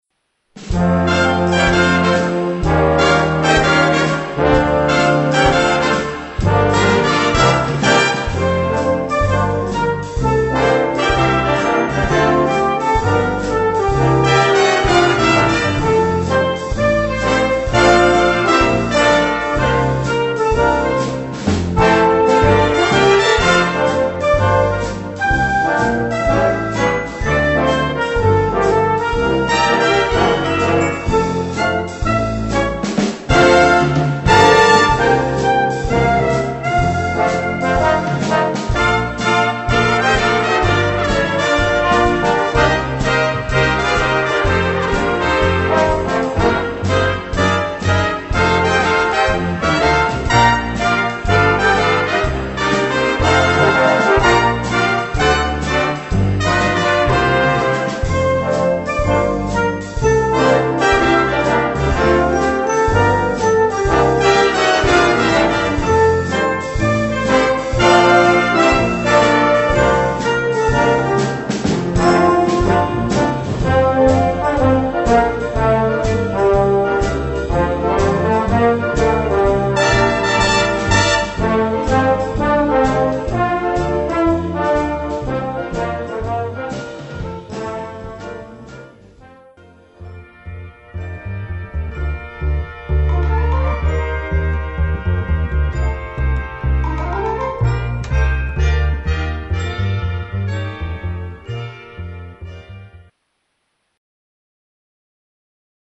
Gattung: Weihnachtsmusik (Swing)
Besetzung: Blasorchester